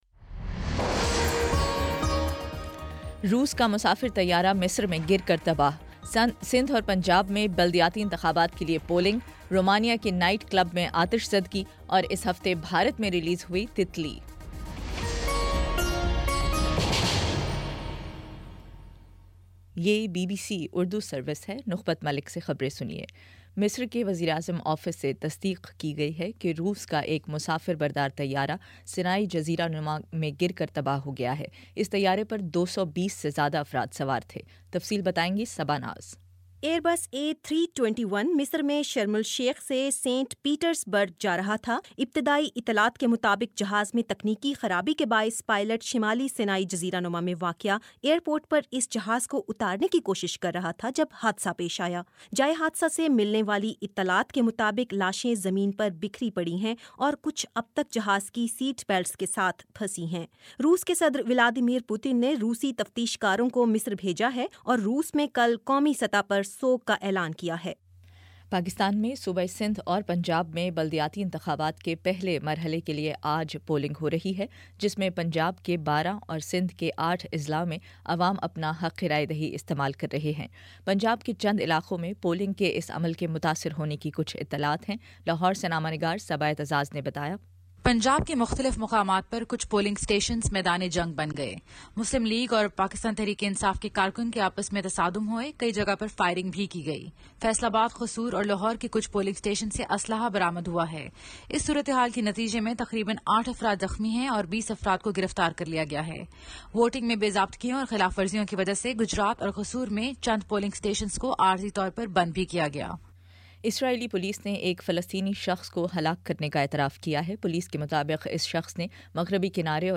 اکتوبر 31 : شام پانچ بجے کا نیوز بُلیٹن